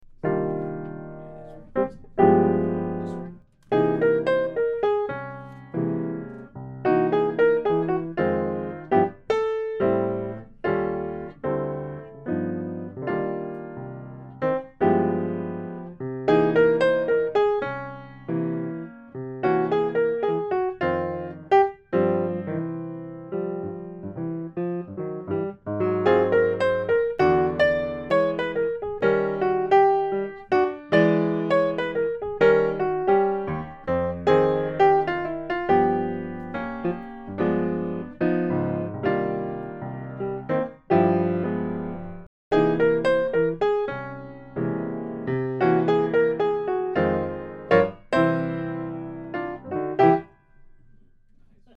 at a medium swing tempo